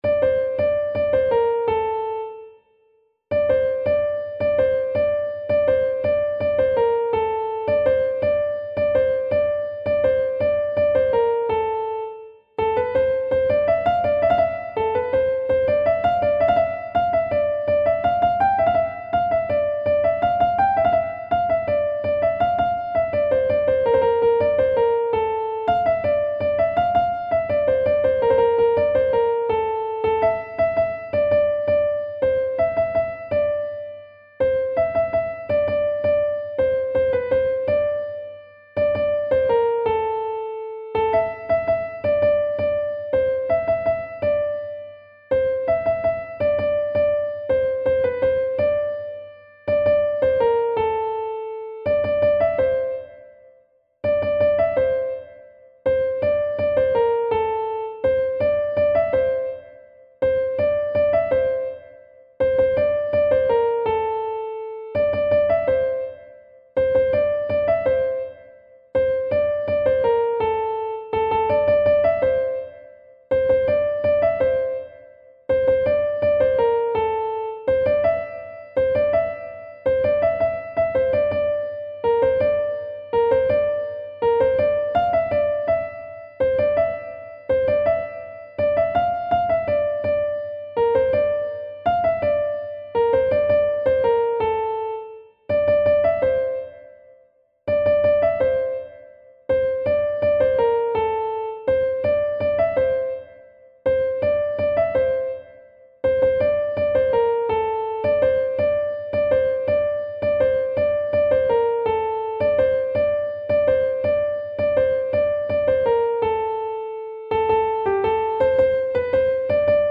نت کیبورد